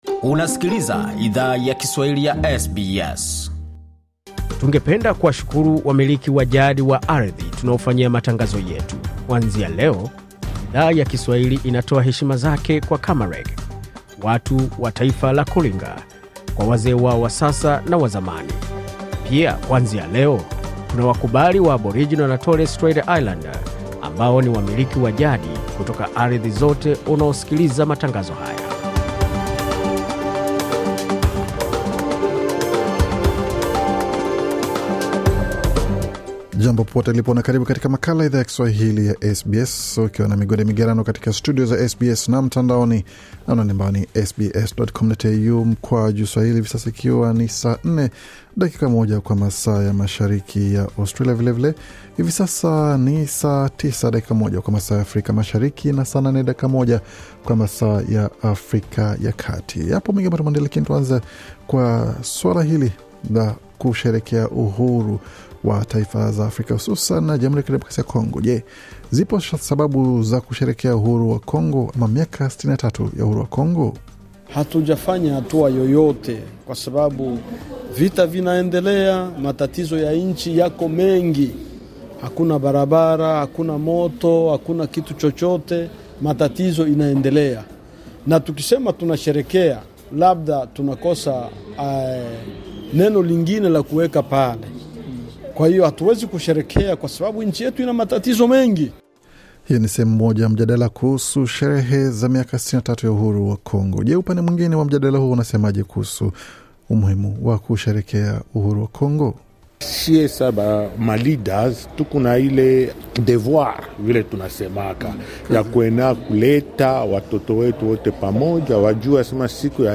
Taarifa ya Habari 2 Julai 2023